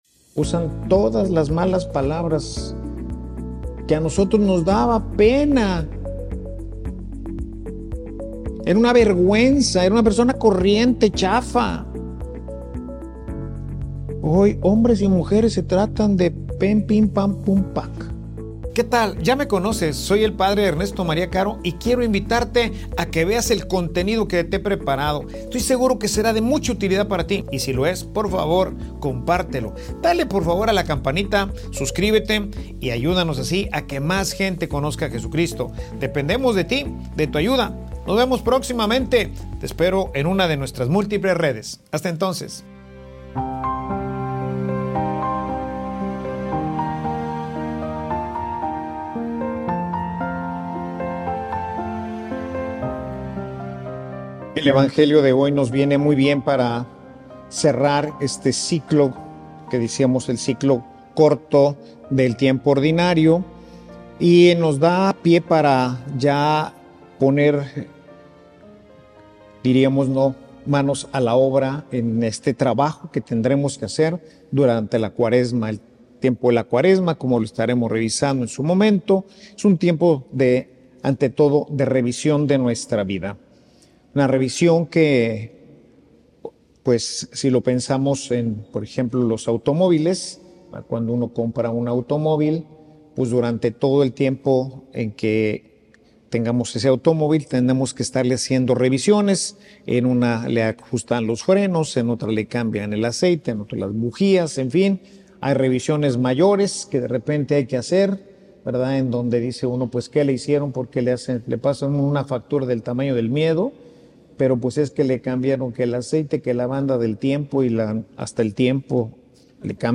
Homilia_Quien_domina_en_tu_corazon.mp3